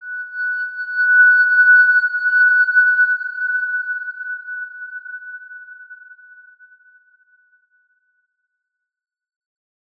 X_Windwistle-F#5-mf.wav